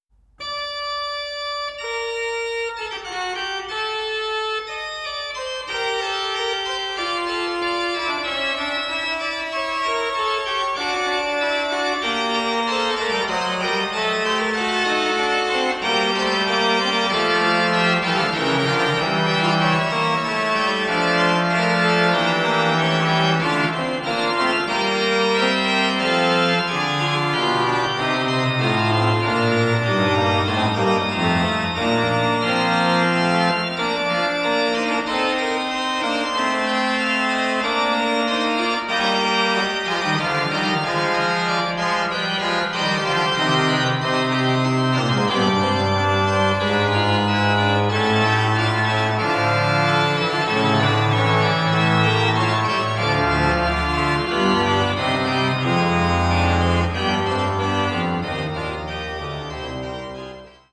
Matériel pour enregistrement d'orgue